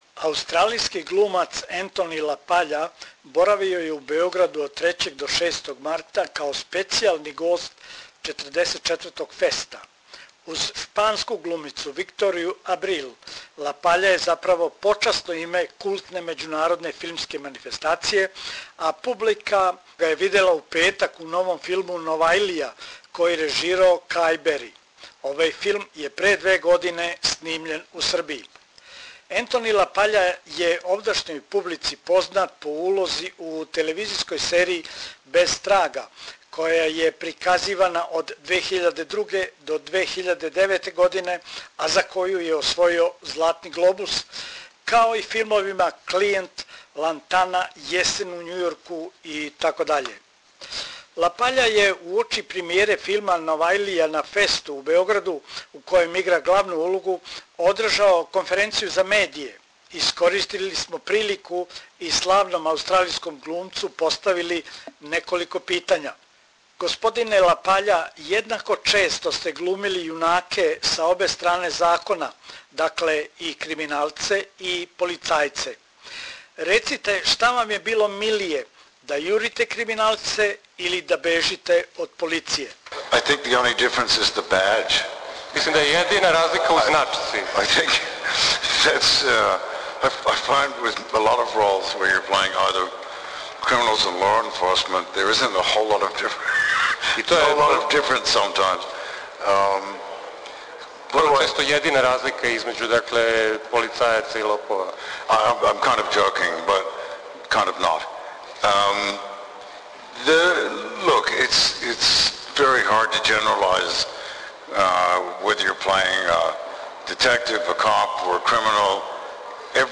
Аустралијски глумац Anthony LaPaglia, био је гост 44. Феста. На конференциј иза штампу у Беграду рекао је да је Београд отворен град и да се осећа фантастично у Београду.